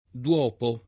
vai all'elenco alfabetico delle voci ingrandisci il carattere 100% rimpicciolisci il carattere stampa invia tramite posta elettronica codividi su Facebook duopo [ d U0 po ] s. m. — gf. unita per d’uopo : cfr. uopo